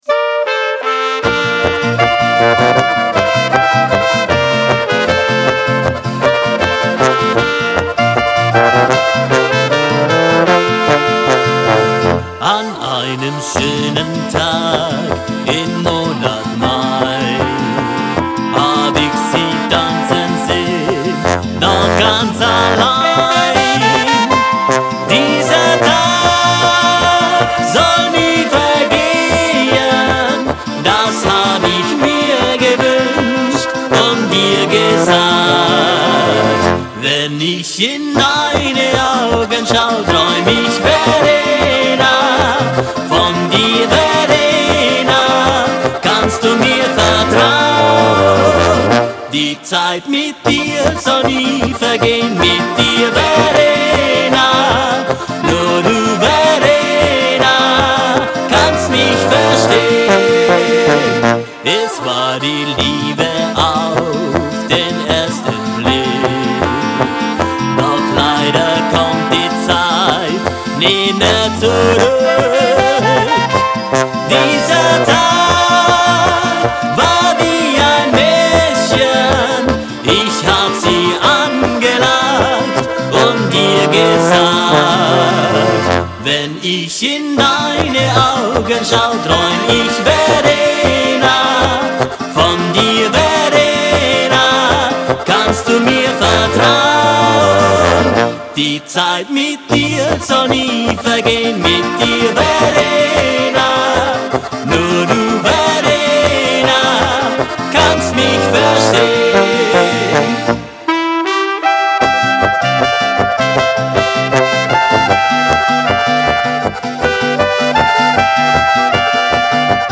Polkalied